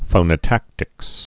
(fōnə-tăktĭks)